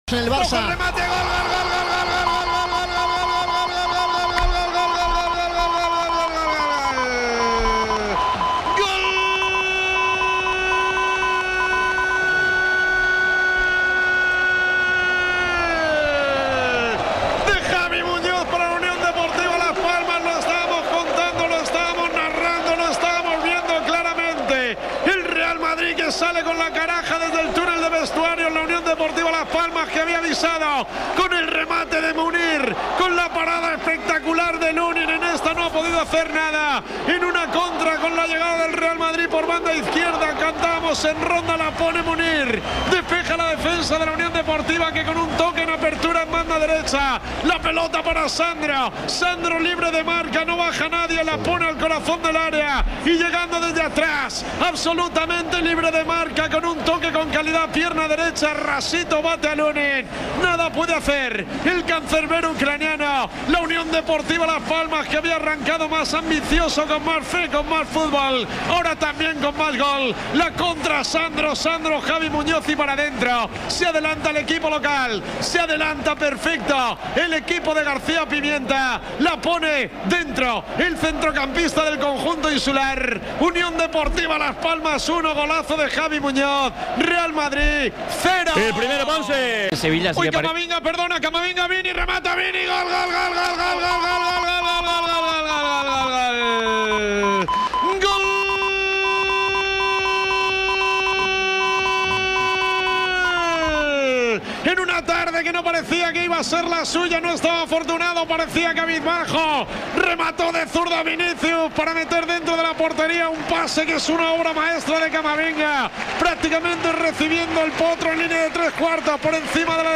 El Real Madrid continúa con la buena dinámica en LaLiga gracias a los tantos de Vinicius y Tchouaméni que te narramos en Carrusel Deportivo.